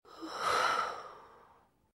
sigh2.ogg